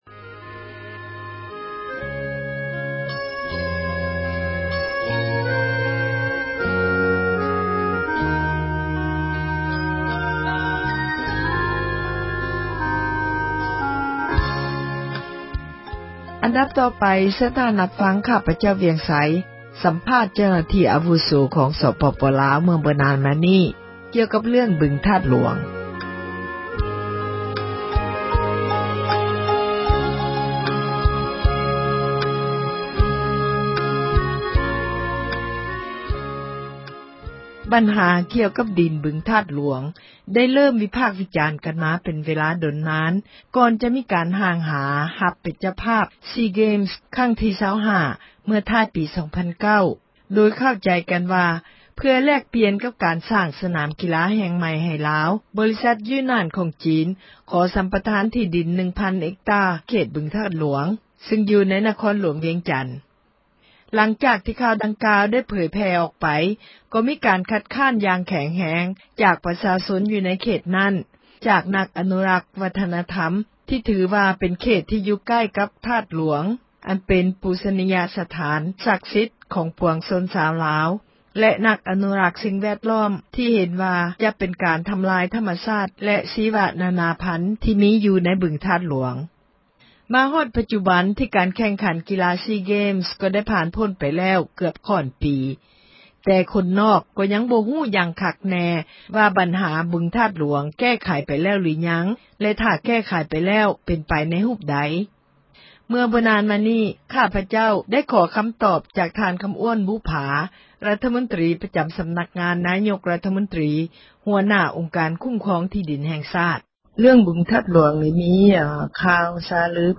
ສັມພາດເຈົ້າໜ້າທີ່ລາວ ເຣື້ອງບຶງທາດຫລວງ
F-Kham-Ouane ທ່ານຄໍາອ້ວນບຸບຜາ ຣັຖມົນຕຣີ ປະຈໍາສໍານັກງານ ນາຍົກຣັຖມົນຕຣີ ຫົວໜ້າອົງການ ຄູ້ມຄອງທີ່ດິນ ແຫ່ງຊາດ ກໍາລັງໃຫ້ສັມພາດ ແກ່ຜູ້ສື່ຂ່າວ ວິທຍຸເອເຊັຽເສຣີ ກ່ຽວກັບເຣື້ອງ ບຶງທາດຫຼວງ